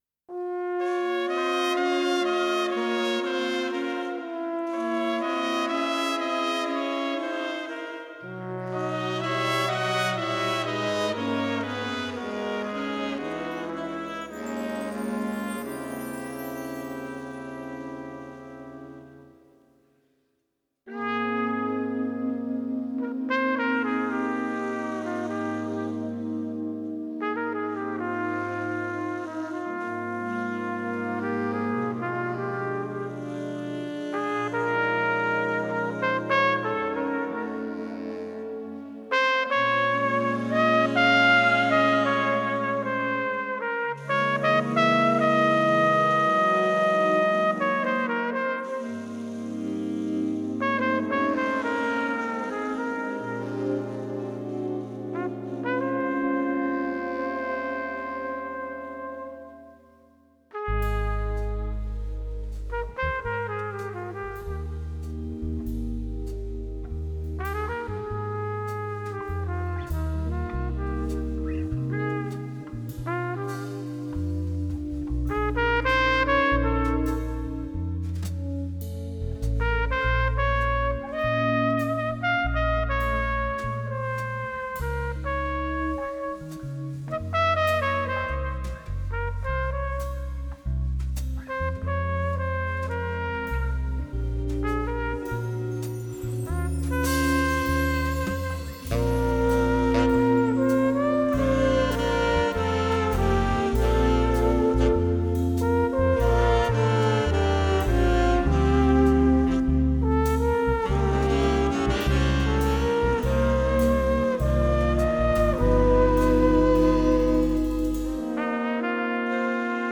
Тип альбома: Студийный
Жанр: Post-Bop
trumpet